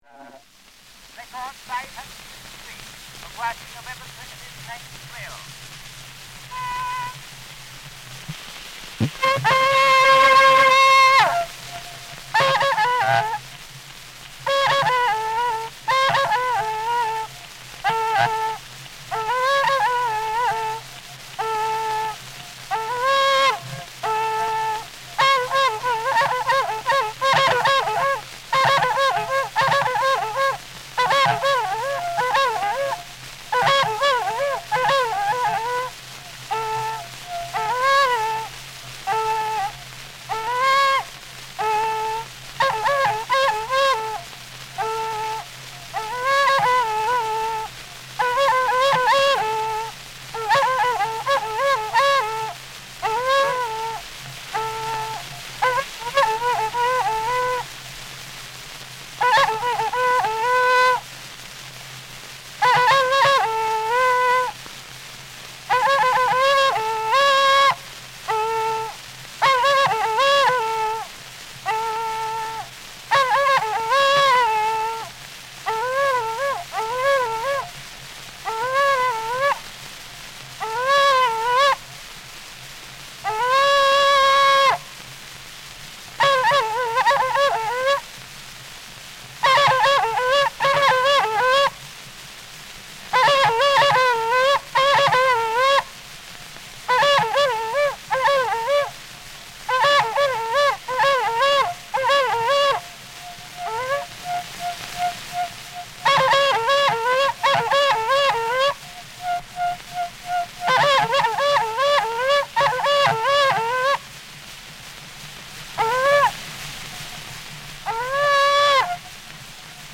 Igbo wind instrument
being from a collection of wax cylinder recordings of songs and spoken language
in Nigeria and Sierra Leone between 1909 and 1915